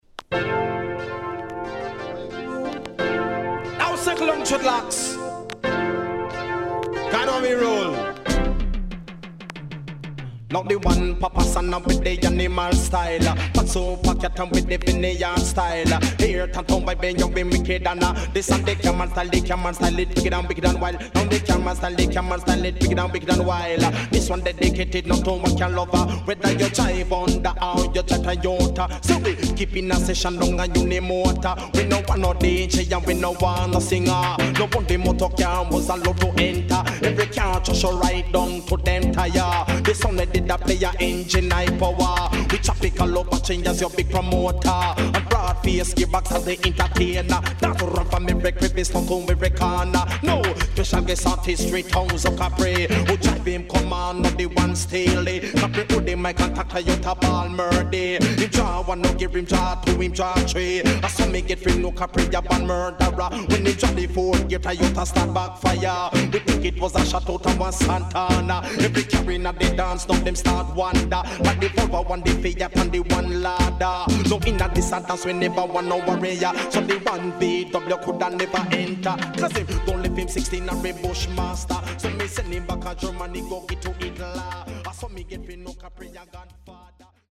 HOME > Back Order [DANCEHALL DISCO45]
SIDE B:少しチリノイズ、プチノイズ入ります。